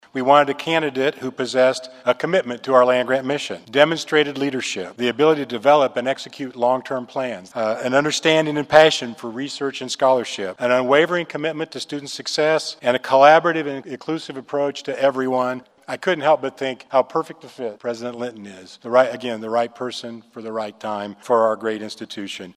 President Richard Linton officially took on the title following a ceremony held in McCain Auditorium.